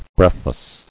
[breath·less]